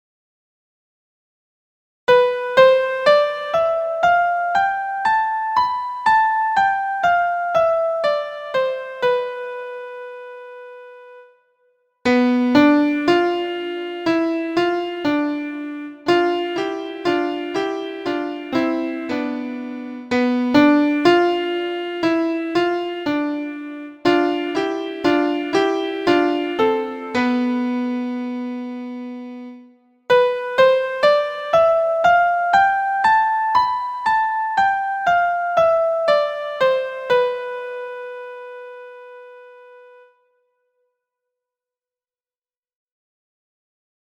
LOCRIAN
Locrian.mp3